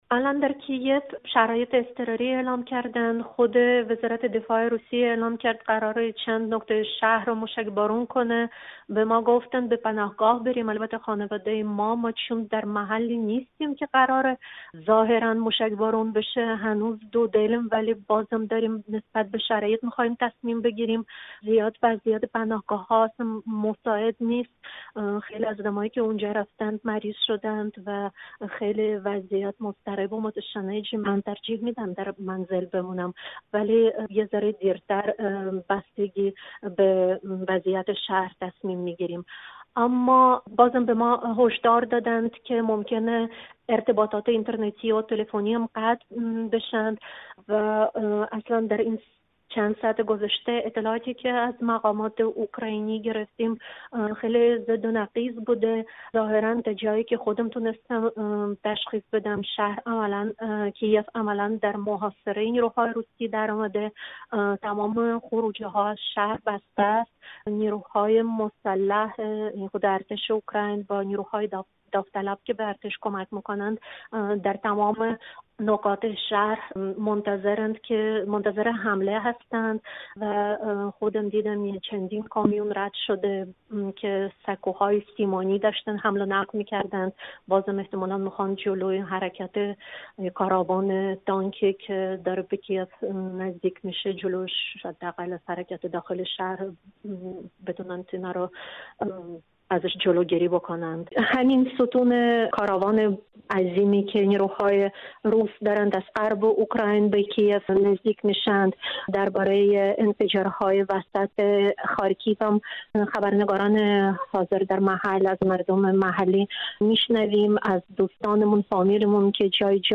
گزارش خبرنگار رادیو فردا از کی‌یف؛ شهر محاصره است